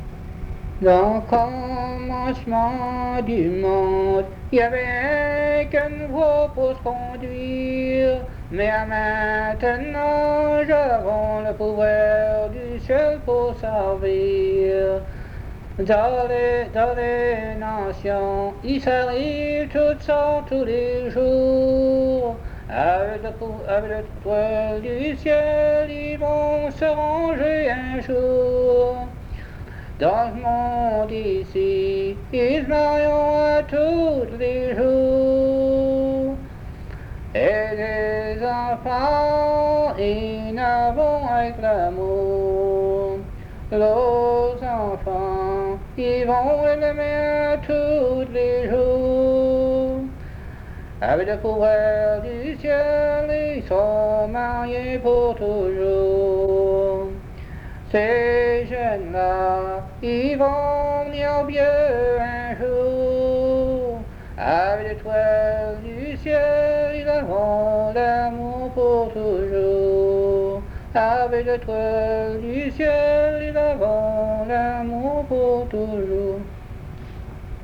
Chanson Item Type Metadata